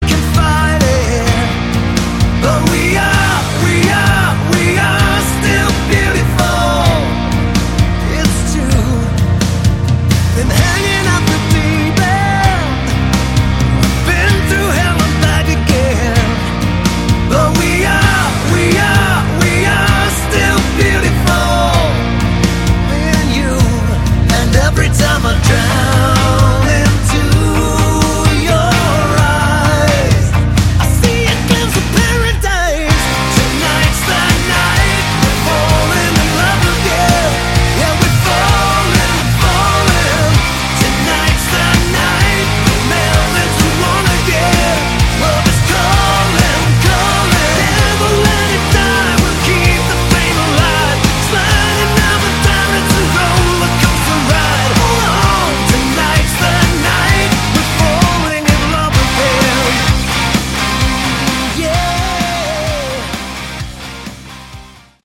Category: Melodic Hard Rock
lead and background vocals
lead guitar, bass, keyboards, background vocals
drums